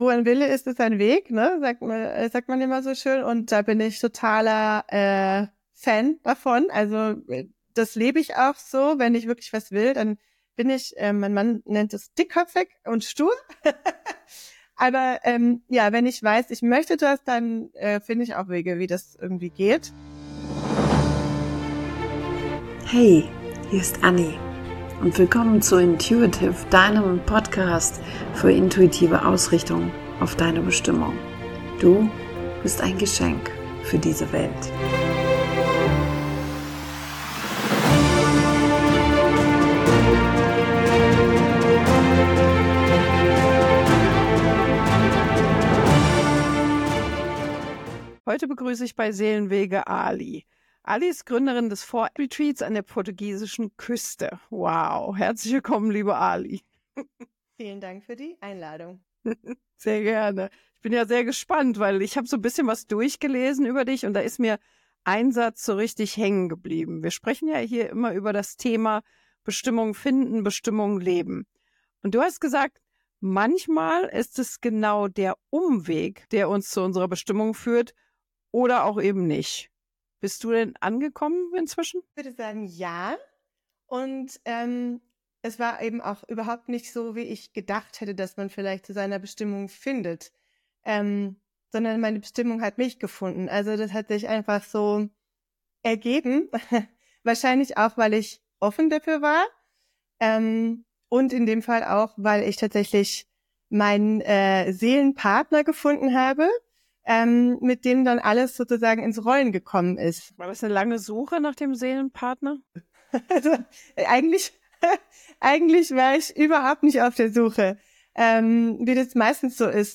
Seelenwege Interview